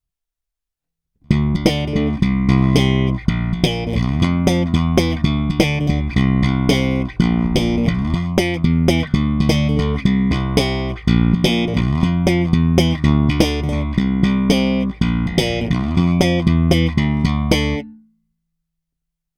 Ukázky jsou nahrány rovnou do zvukové karty a jen normalizovány.
Slap na oba snímače